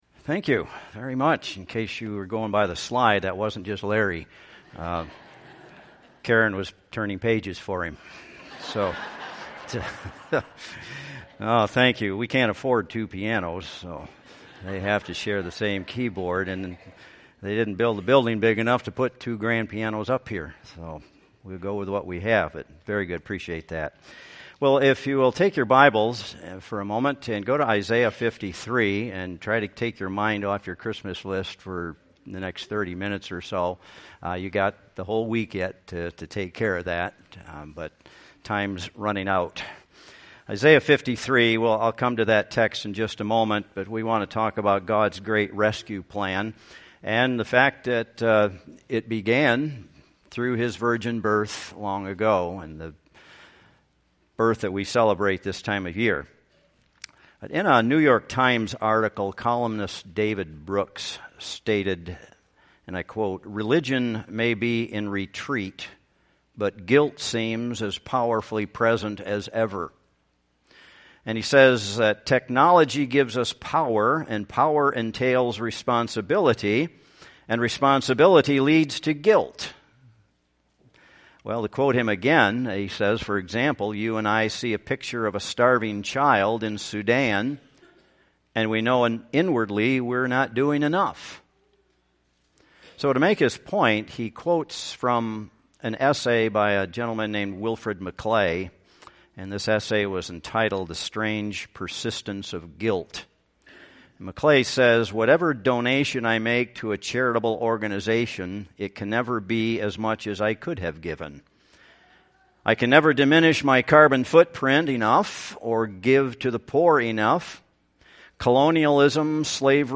Passage: Isaiah 53:1-3,10-11 Service Type: Worship Service